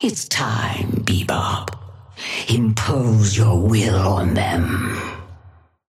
Sapphire Flame voice line - It's time, Bebop. Impose your will on them.
Patron_female_ally_bebop_start_01.mp3